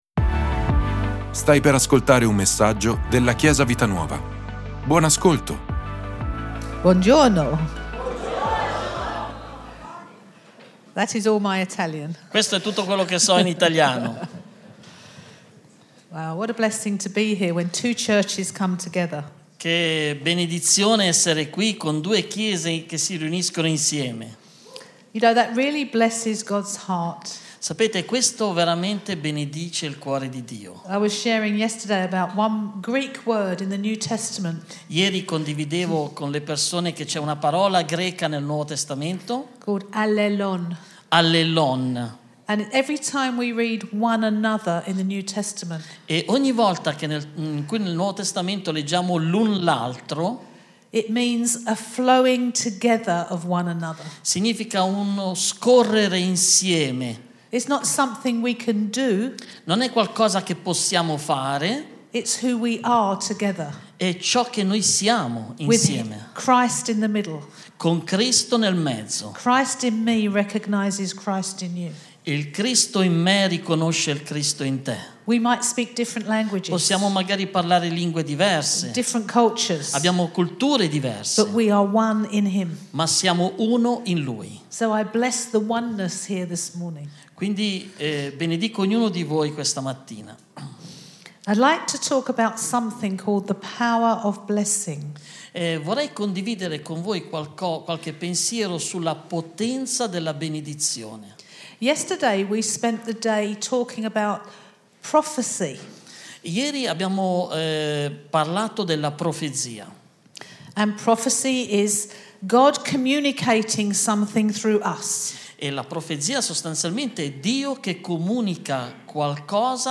Ascolta la predicazione: Il potere della benedizione - Chiesa Vita Nuova